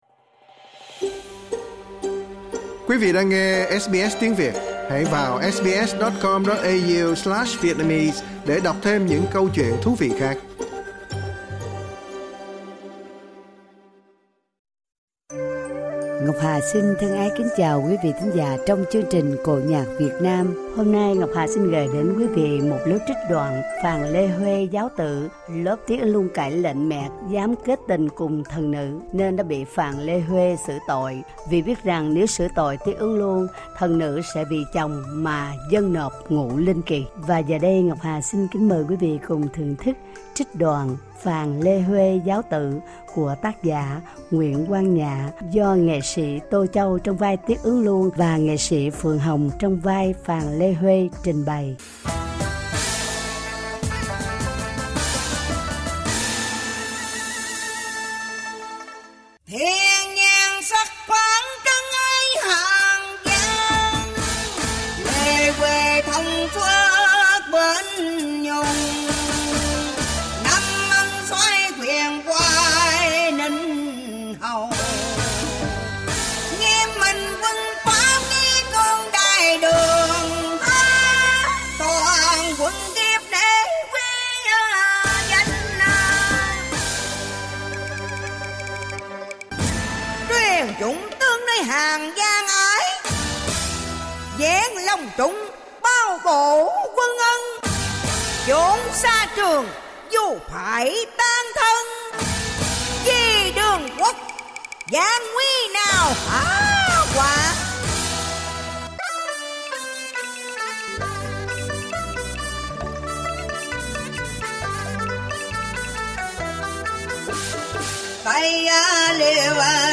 trích đoạn cải lương hồ quảng